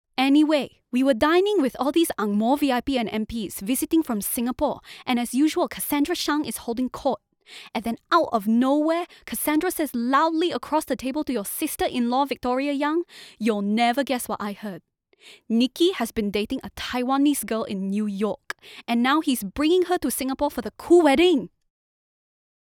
singapore | natural
singlish